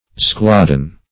Search Result for " zeuglodon" : The Collaborative International Dictionary of English v.0.48: Zeuglodon \Zeu"glo*don\, n. [Gr.